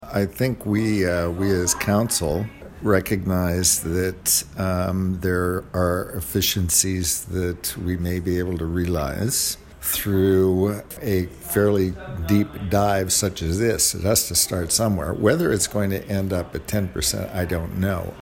Mayor Steve Ferguson says this is a very worthwhile endeavour and a long overdue process.